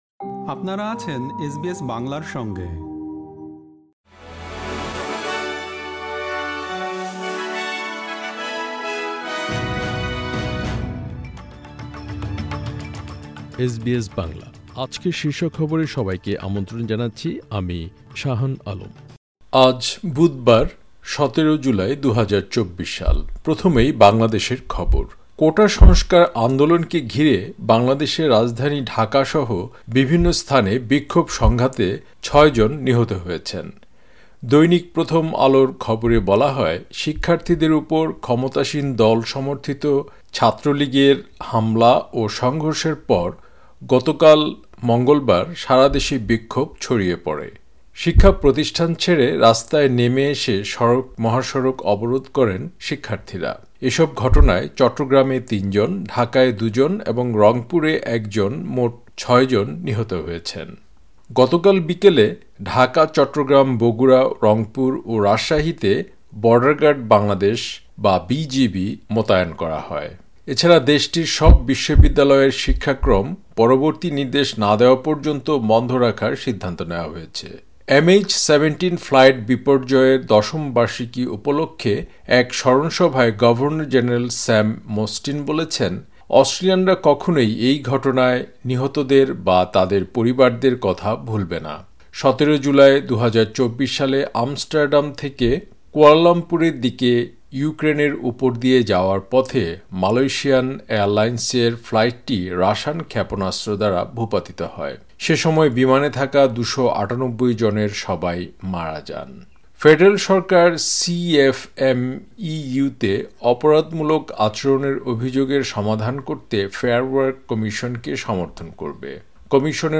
এসবিএস বাংলা শীর্ষ খবর: ১৭ জুলাই, ২০২৪